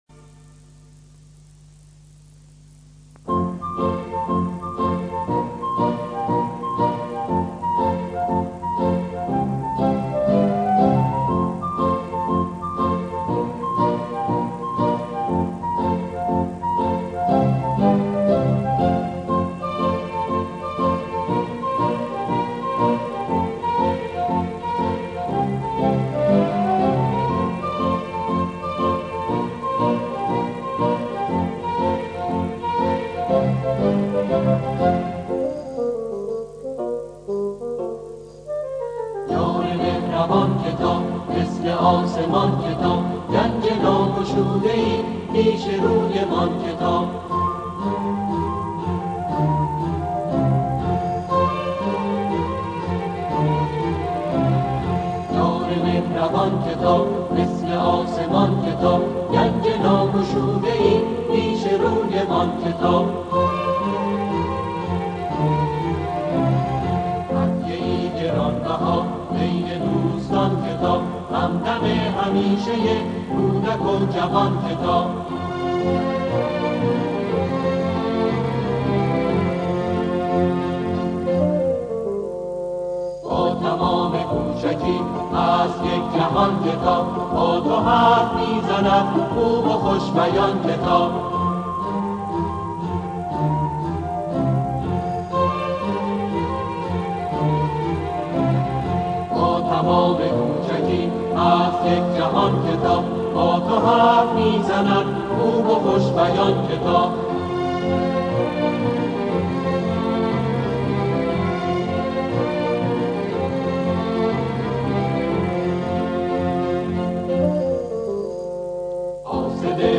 این اثر با اجرای گروه کر و تهیه شده در آموزش و پرورش است.